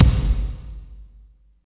32.09 KICK.wav